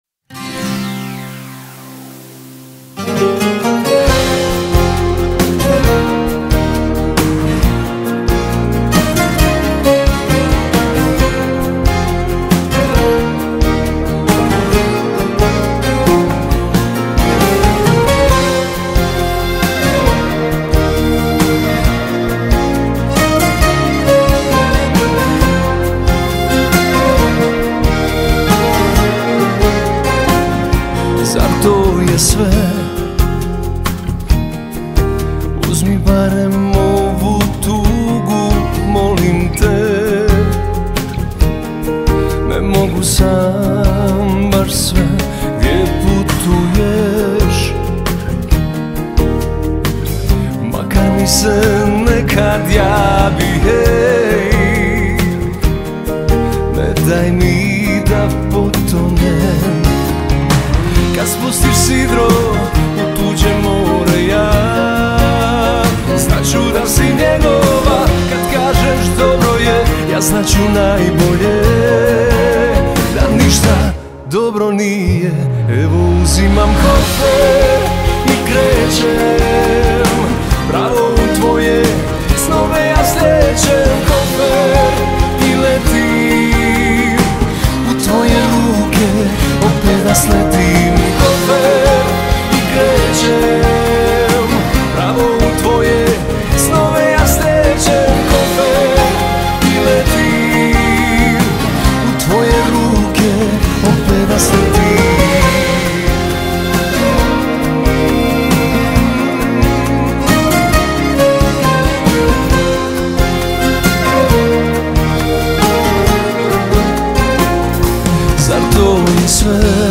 сочетая элементы поп-музыки и традиционных мелодий региона.